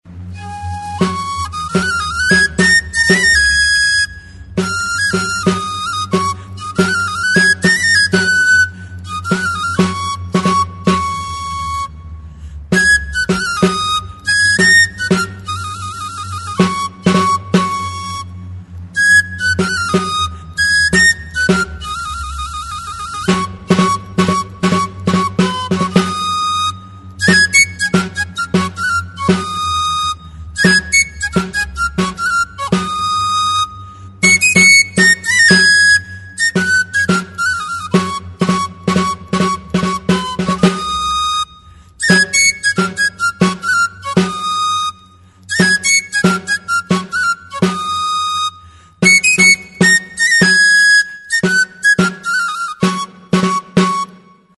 Instruments de musiqueTXISTUA
Aérophones -> Flûtes -> Á Bec (á une main)
Enregistré avec cet instrument de musique.
Hiru zuloko flauta zuzena da.
Fa# tonuan dago.